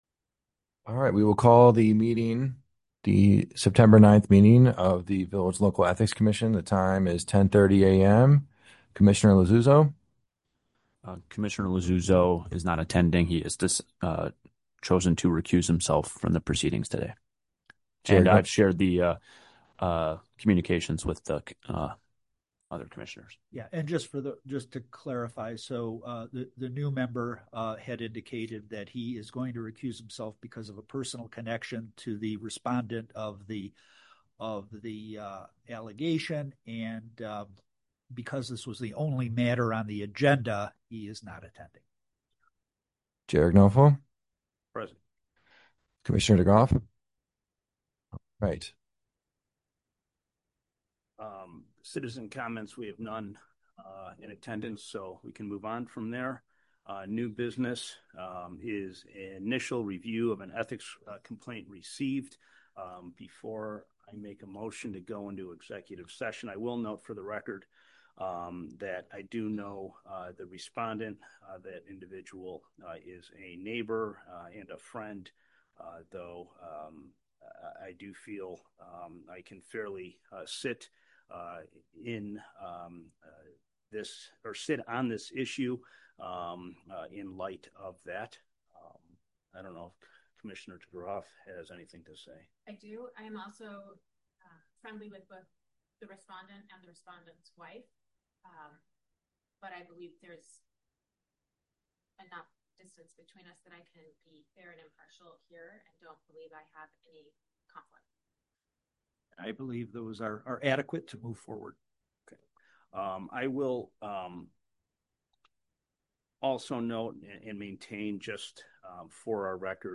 Special Meeting of the Local Ethics Commission
10::30AM, Community Room - Village Hall - 400 Park Avenue